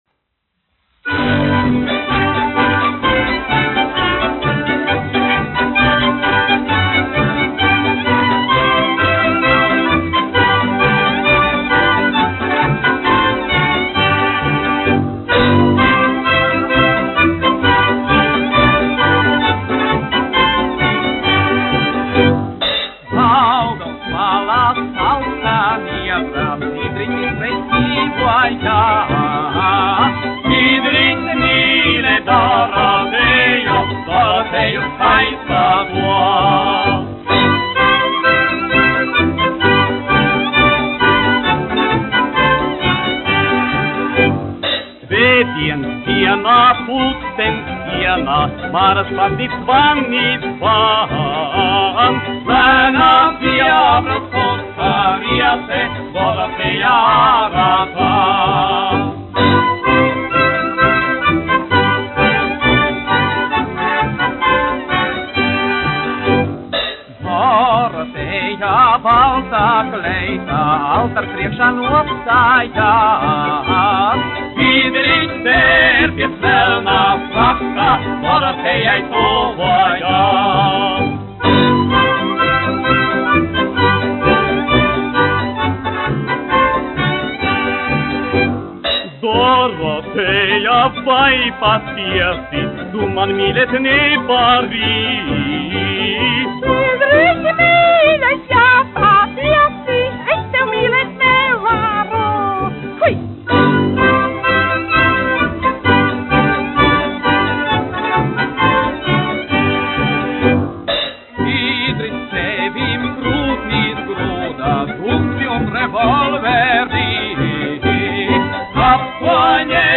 1 skpl. : analogs, 78 apgr/min, mono ; 25 cm
Humoristiskās dziesmas
Populārā mūzika
Skaņuplate